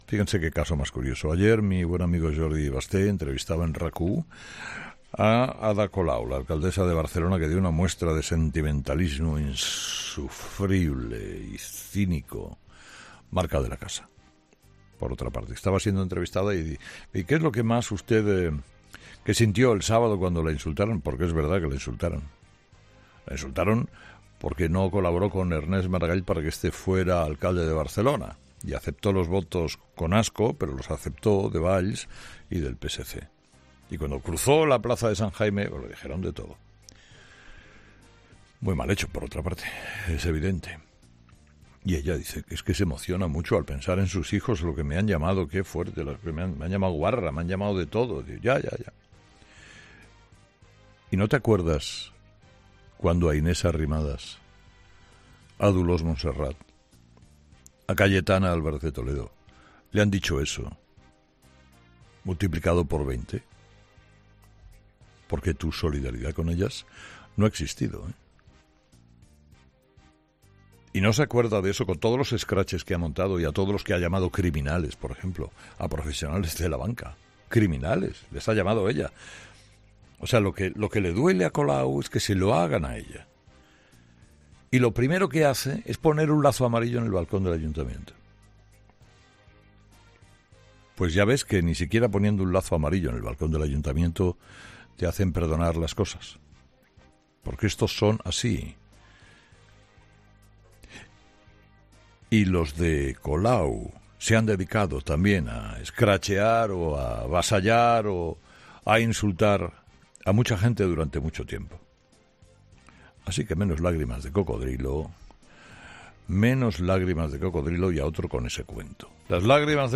En su programa de este miércoles, Carlos Herrera se ha solidarizado con Ada Colau e, irónicamente, ha roto también a llorar en directo.